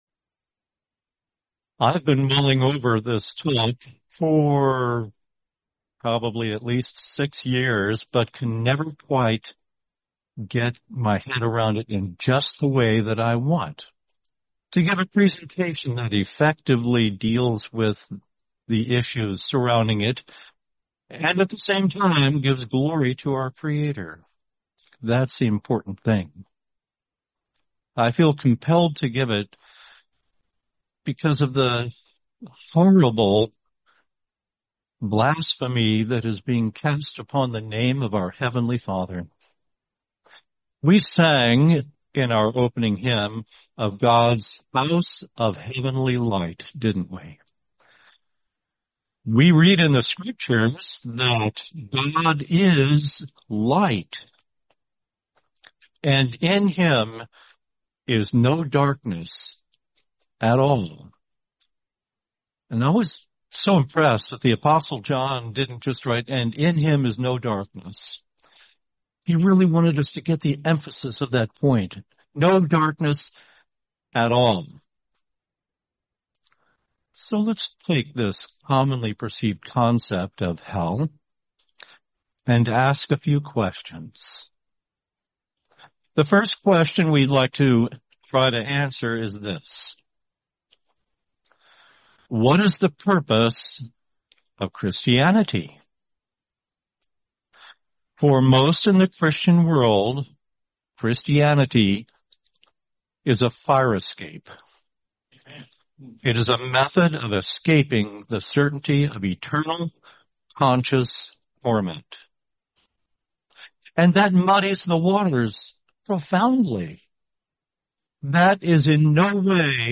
Series: 2026 Sacramento Convention